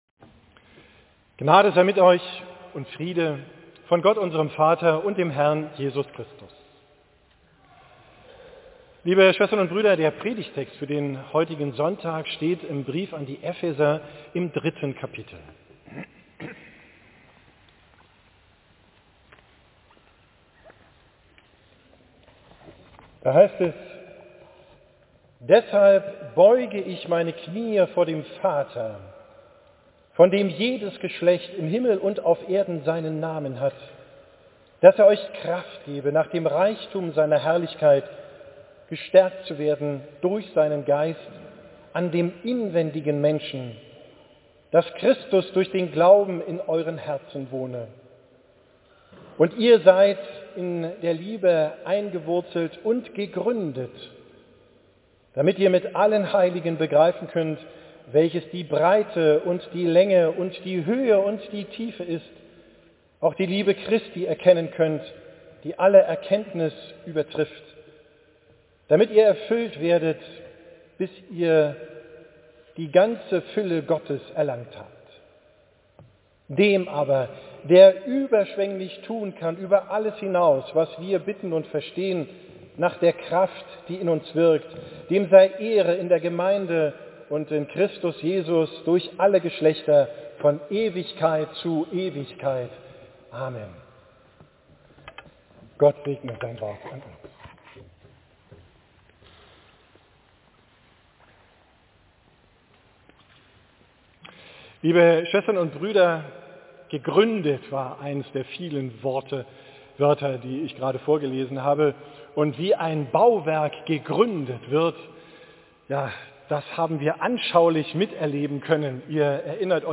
Predigt vom Sonntag Exaudi, 1.